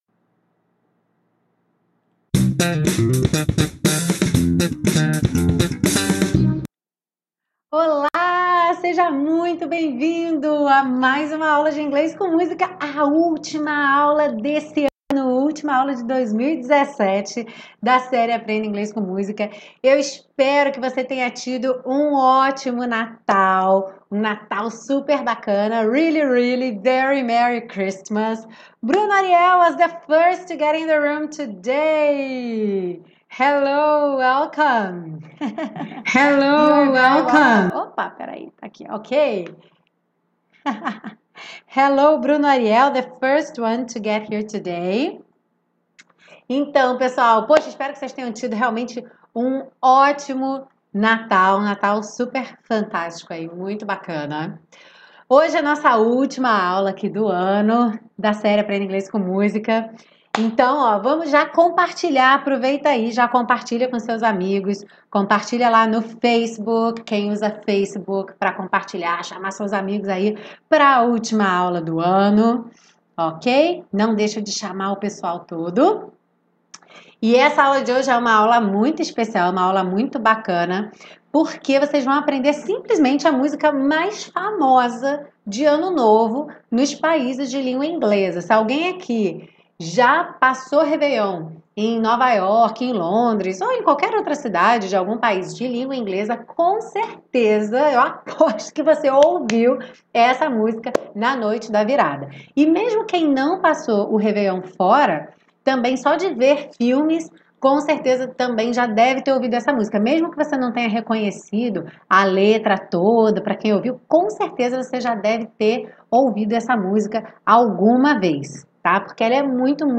Auld Lang Syne - aula AO VIVO